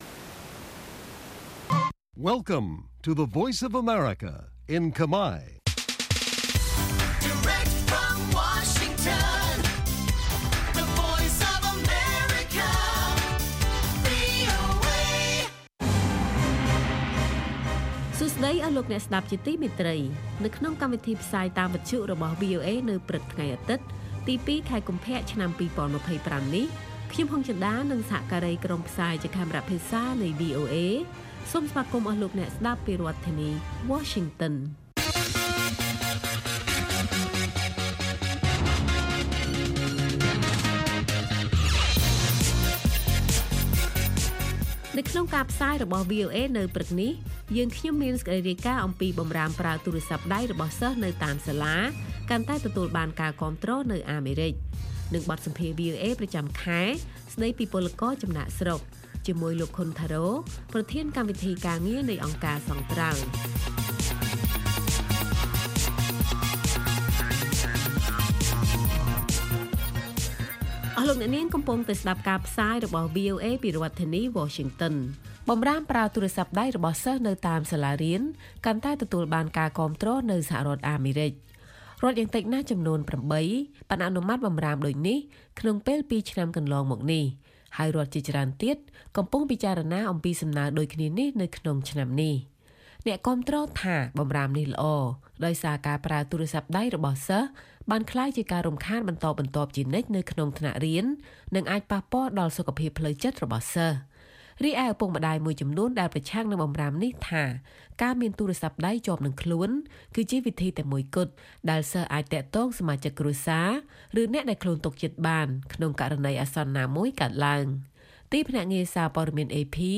ព័ត៌មាននៅថ្ងៃនេះមានដូចជា បម្រាមប្រើទូរស័ព្ទដៃរបស់សិស្សនៅតាមសាលាកាន់តែទទួលបានការគាំទ្រនៅអាមេរិក។ បទសម្ភាសន៍ VOA ប្រចាំខែស្តីពី «ពលករចំណាកស្រុក»